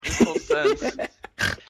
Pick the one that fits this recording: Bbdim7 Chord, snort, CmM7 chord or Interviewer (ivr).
snort